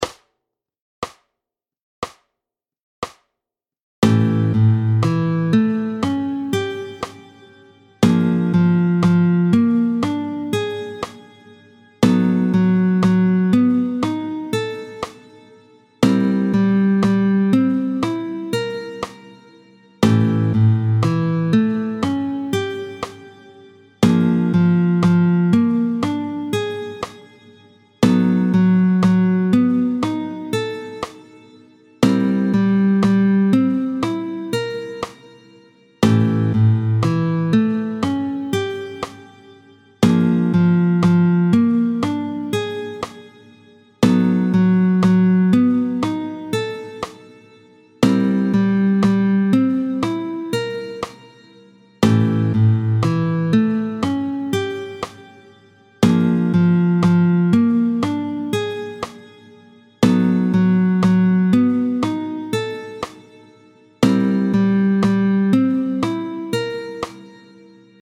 29-01 Petit barré de l’index tempo 60